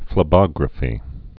(flĭ-bŏgrə-fē)